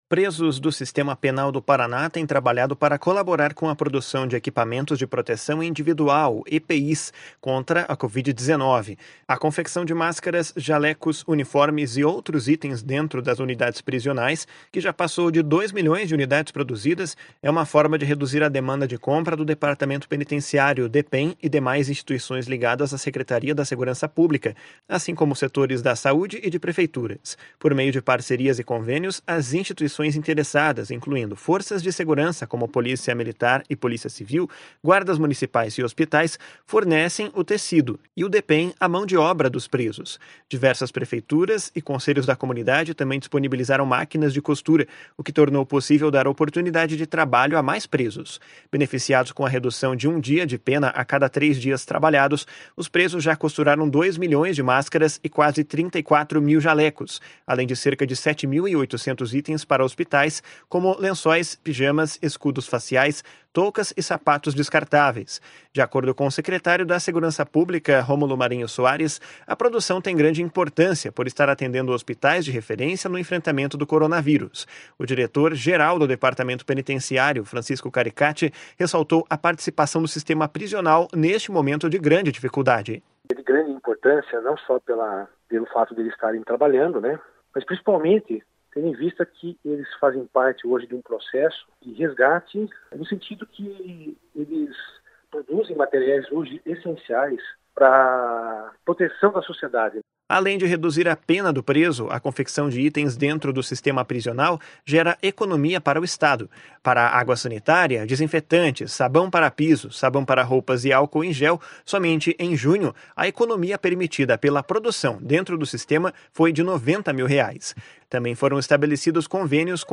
O diretor-geral do Departamento Penitenciário, Francisco Caricati ressaltou a participação do sistema prisional neste momento de grande dificuldade. // SONORA FRANCISCO CARICATI //